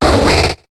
Cri de Goinfrex dans Pokémon HOME.